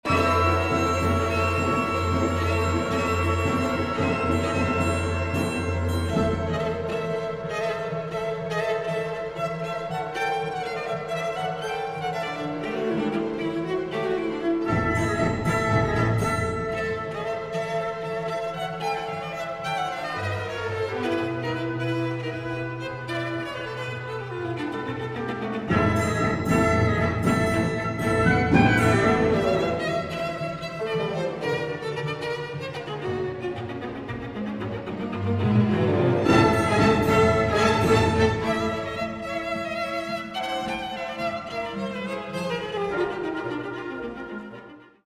a folk-like dance